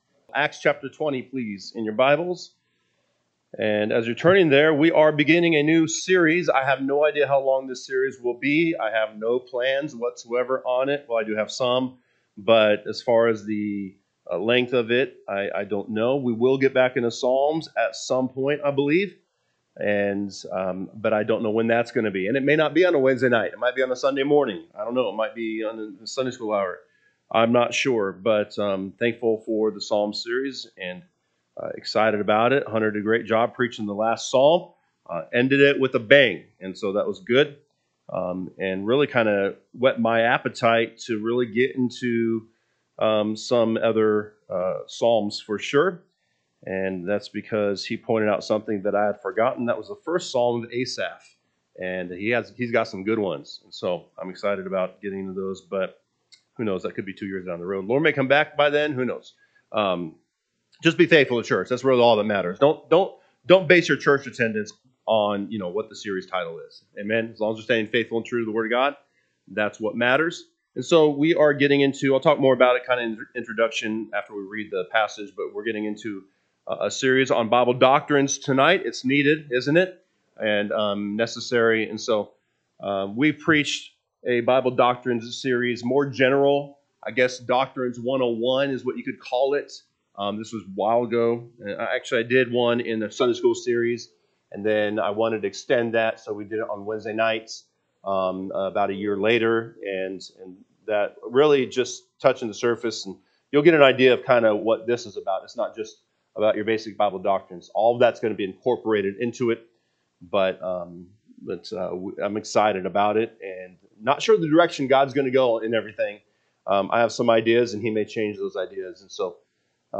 Wednesday Evening